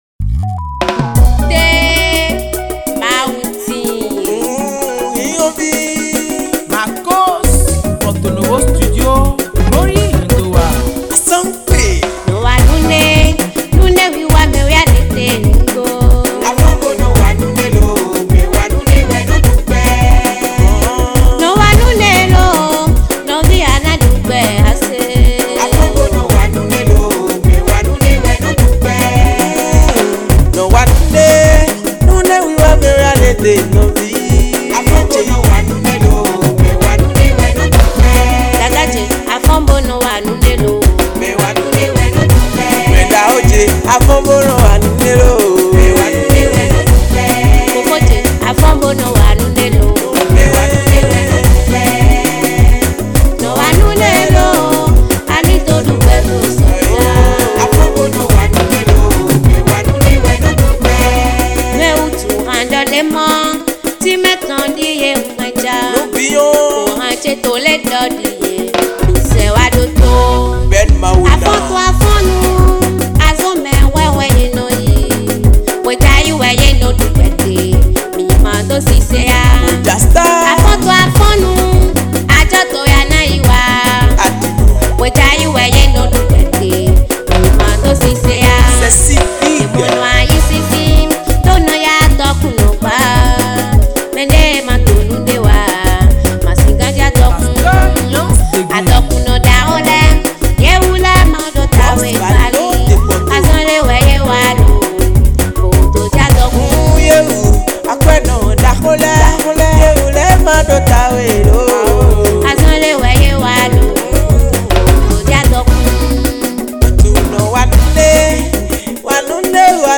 Gospel Mp3